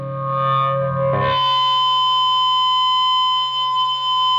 PRS FBACK 1.wav